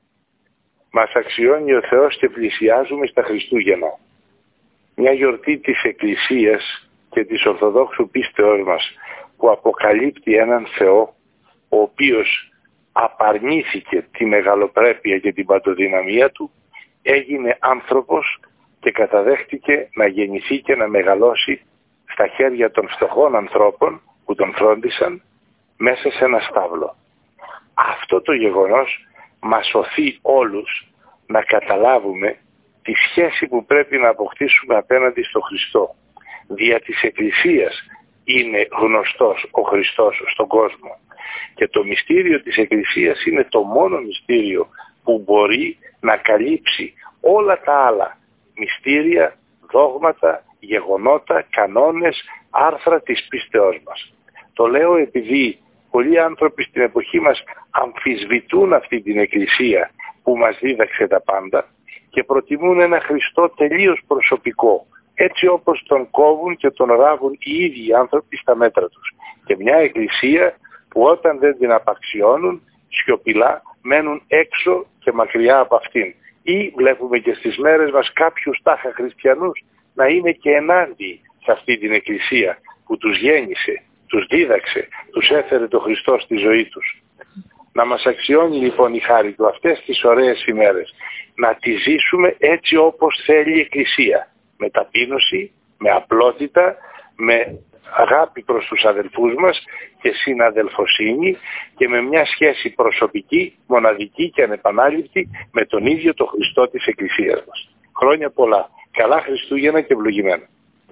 Στους αμφισβητίες της Εκκλησίας μας , στους  ανθρώπους που την απαξιώνουν ή μένουν μακριά της , προτιμώντας έναν δικό τους Χριστό φτιαγμένο στα μέτρα τους αναφέρετε  στο Χριστουγεννιάτικο μήνυμα που απηύθυνε στην ΕΡΤ Ορεστιάδας ο Μητροπολίτης Αλεξανδρουπόλεως Άνθιμος ζητώντας παράλληλα να την αποδεχθούμε ακολουθώντας παράλληλα τους κανόνες της με ταπείνωση απλότητα και αγάπη προς τους αδερφούς μας.
04-Ανθιμος-Mητροπολίτης-Αλεξανδρουπολεως-Ευχές-Χριστουγεννων.mp3